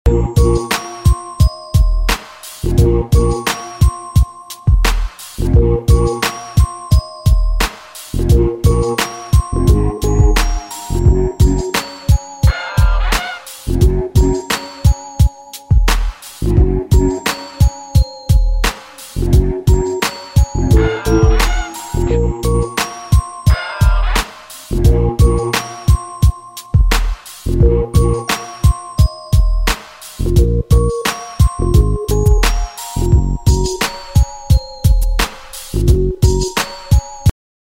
инструментальные